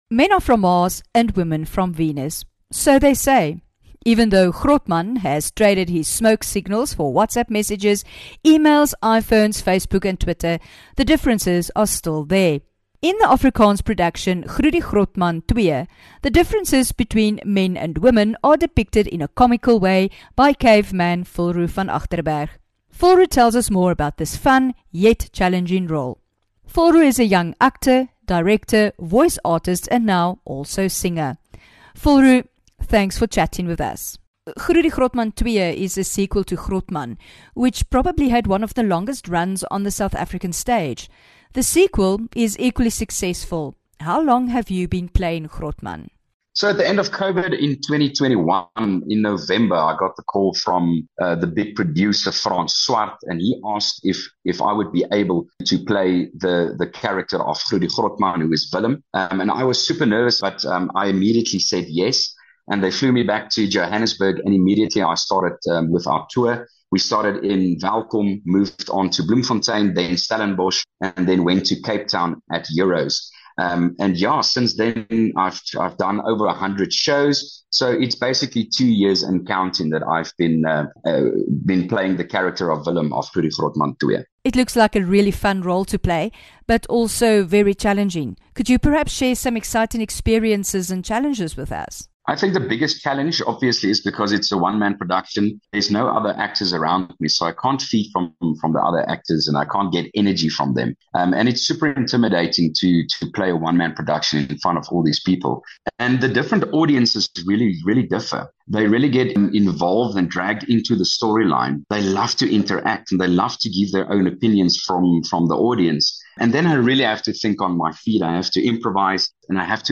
5 Feb INTERVIEW